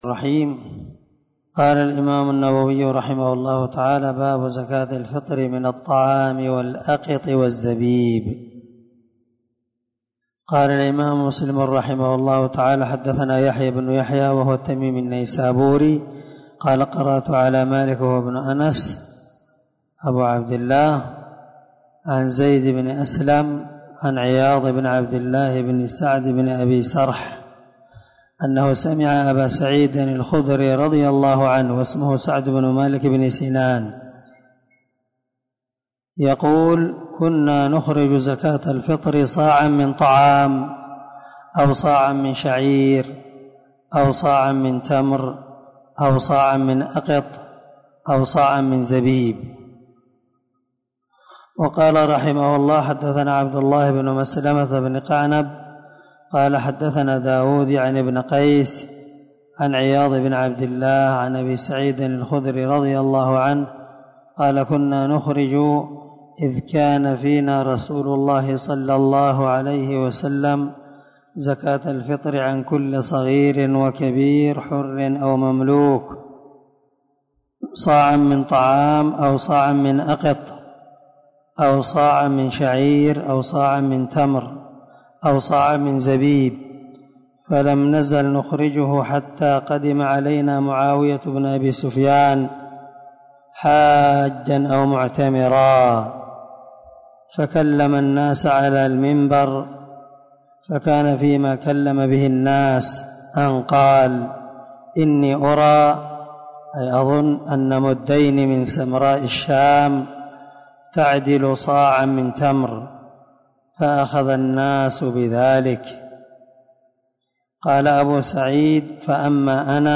598الدرس 6من شرح كتاب الزكاة حديث رقم(985) من صحيح مسلم